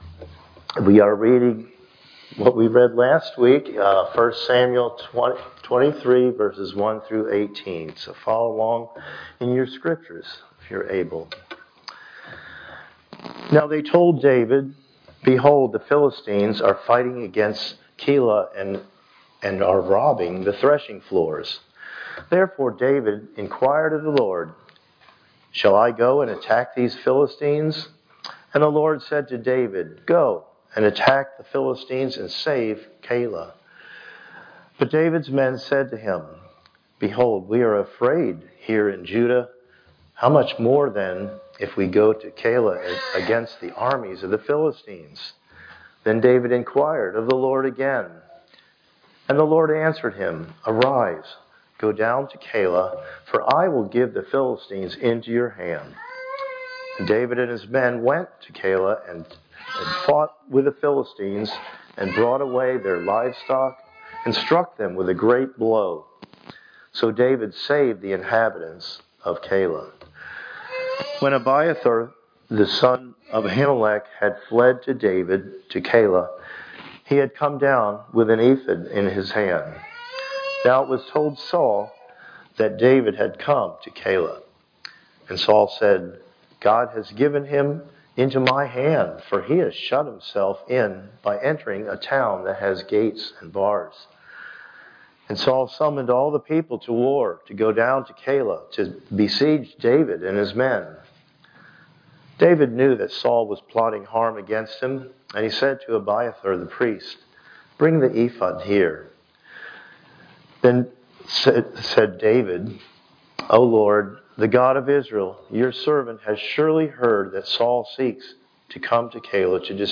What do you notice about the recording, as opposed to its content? Passage: 1 Samuel 23:1-18 Service Type: Sunday Morning Worship